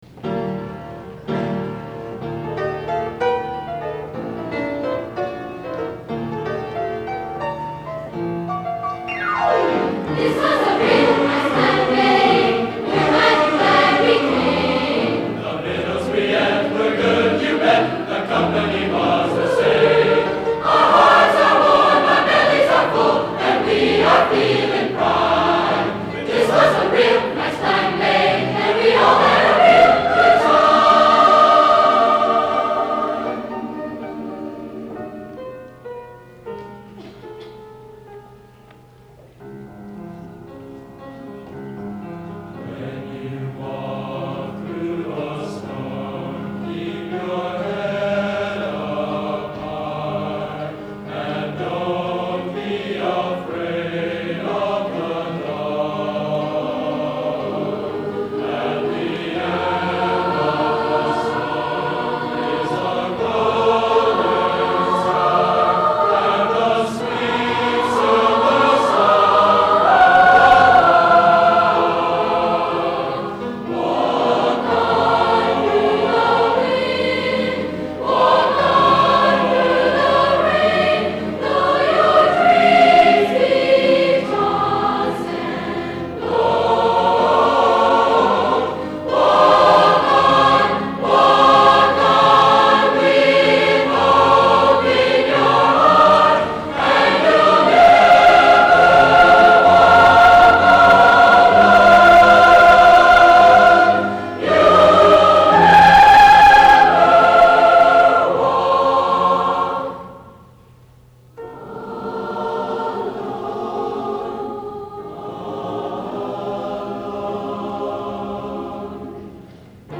Spring Concert
Clay High Gym